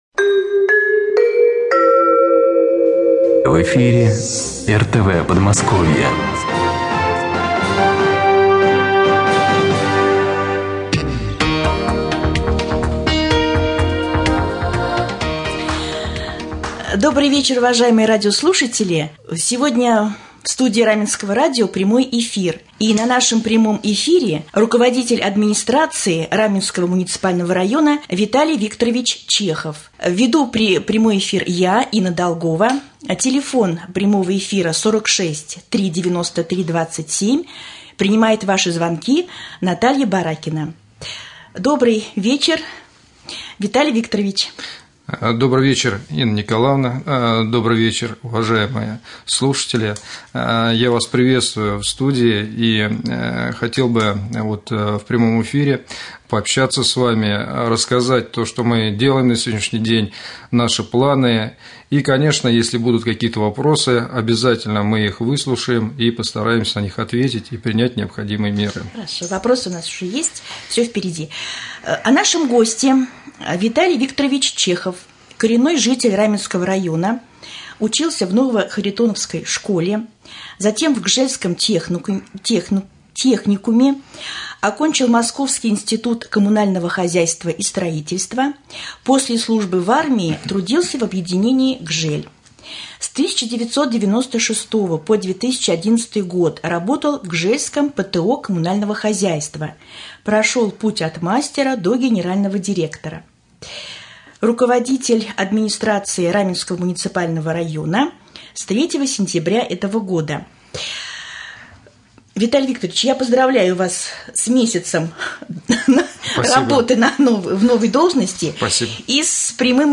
Прямой эфир с руководителем районной администрации Виталием Викторовичем Чеховым